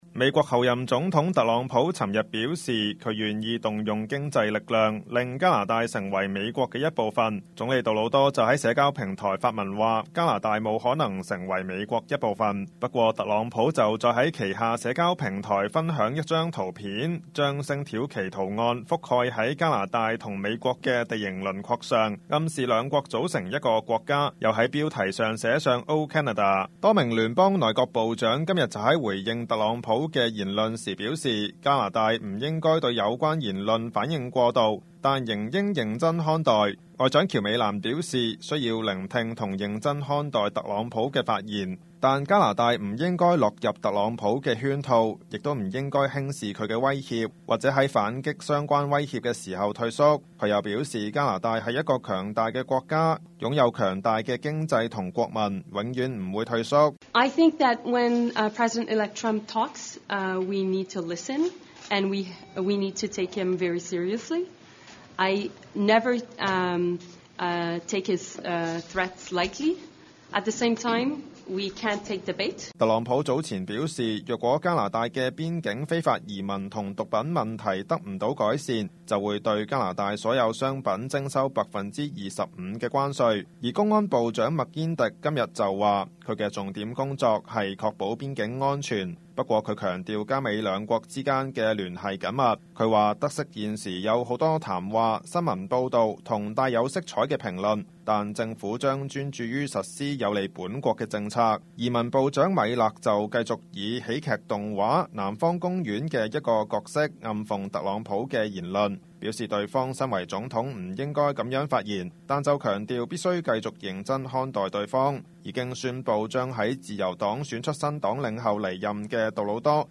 Canada/World News 全國/世界新聞